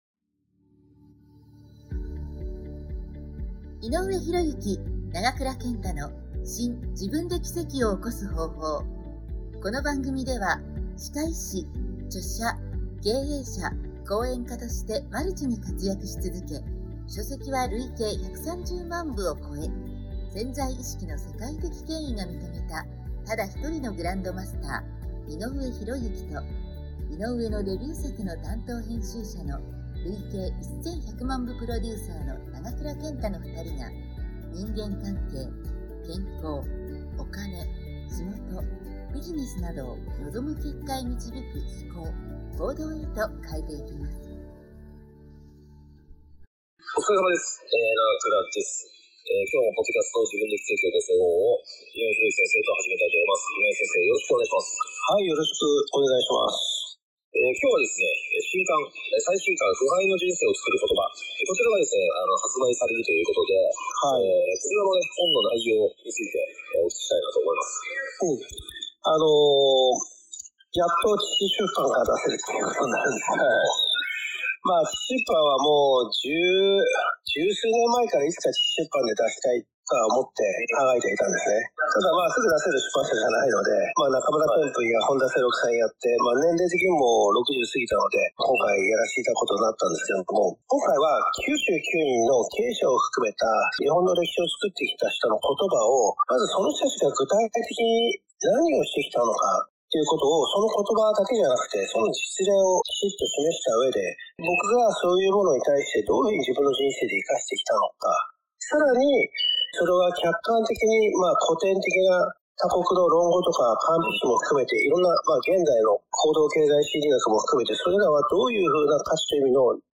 2人による今回のPodcast第272弾は 最新刊『不敗の人生をつくる言葉』について についてお伝えします。